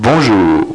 1 channel
bonjour.mp3